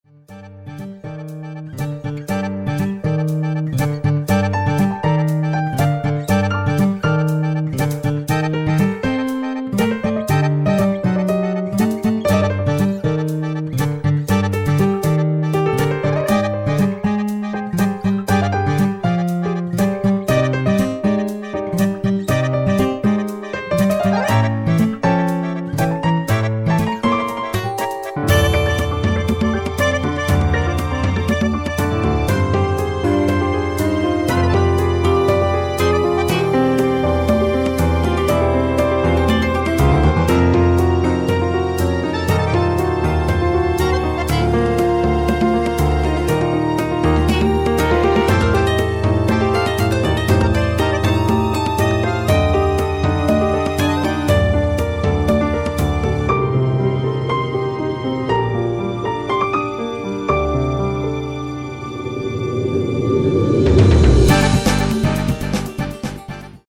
Требуется много жесткой критики) Метал.
Вот одна вещица, пока без голоса....
Старался сделать наоборот погрязнее...